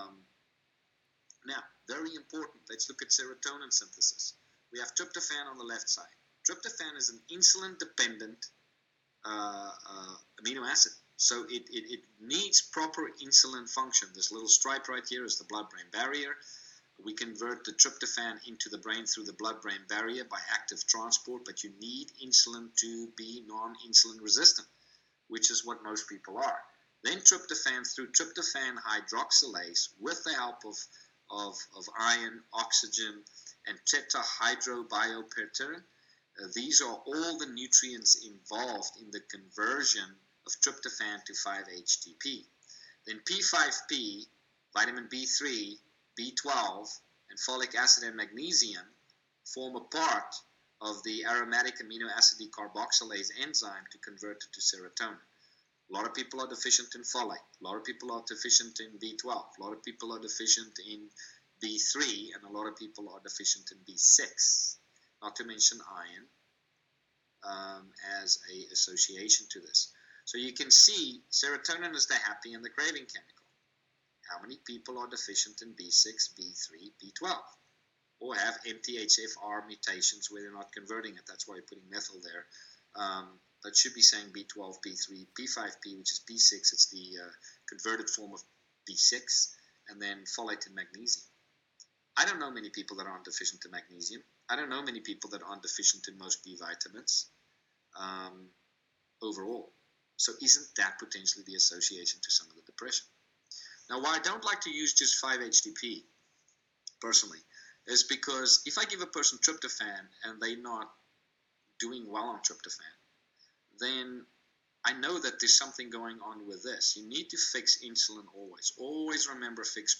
serotonin-synthesis-explained.m4a